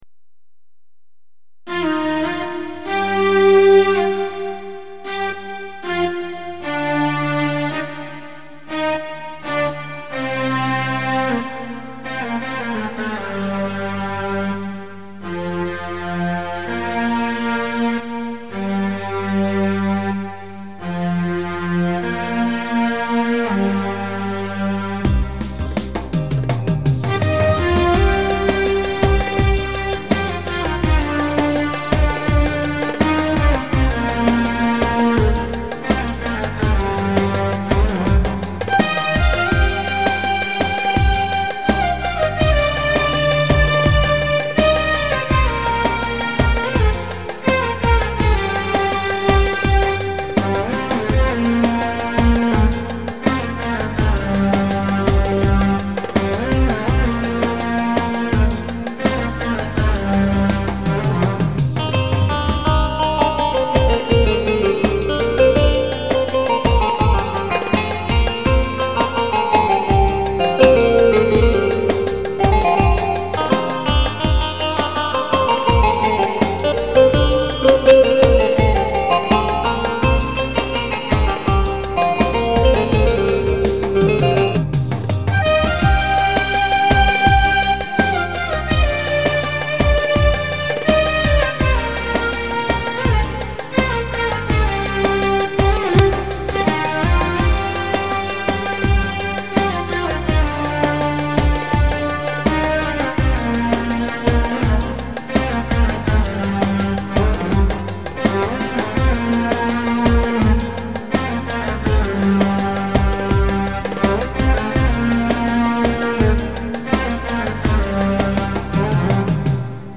Pa500 tr Misket ve Arabesk ...
Ekli dosyalar arabesk1.mp3 1.9 MB · Görülen: 540